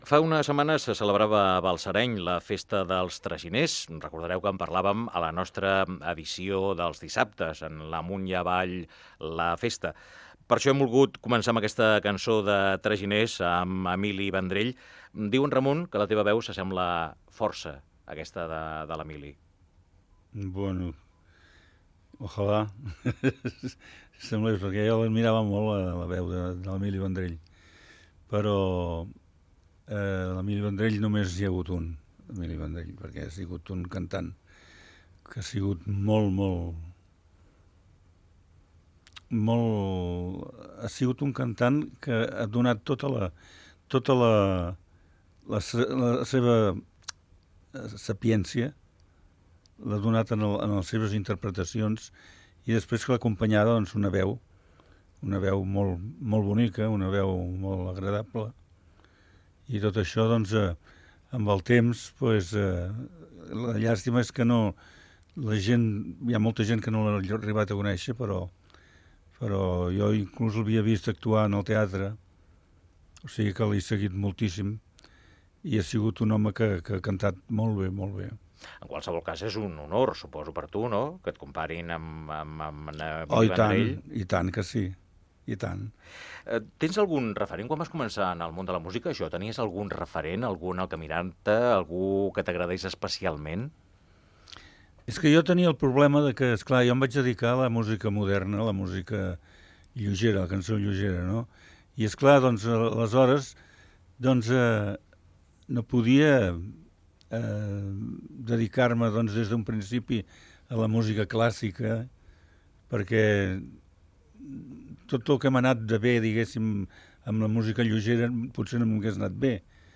Fragment d'una entrevista
Entreteniment